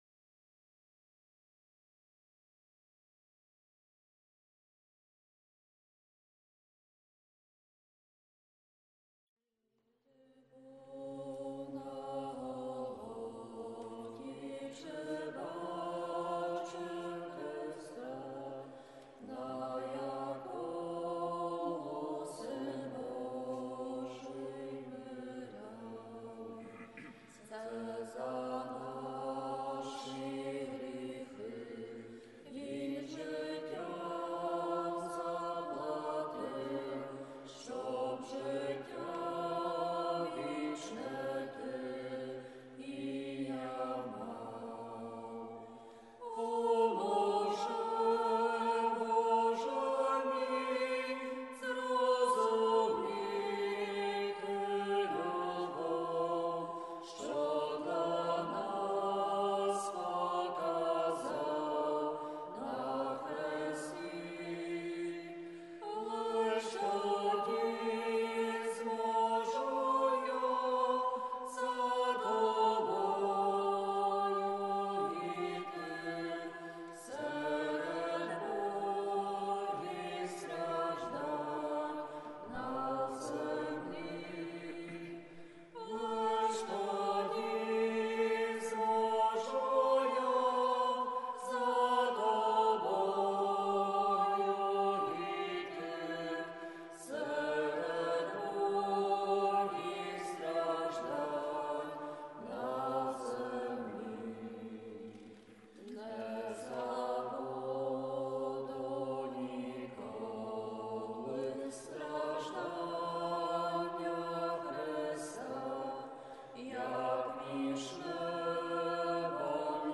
З нашої церкви – V Неділя Посту 05 Квітня 2020 Божественна Літургія Василя Великого – трансляця Радіо Ольштин